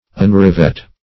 Search Result for " unrivet" : The Collaborative International Dictionary of English v.0.48: Unrivet \Un*riv"et\, v. t. [1st pref. un- + rivet.] To take out, or loose, the rivets of; as, to unrivet boiler plates.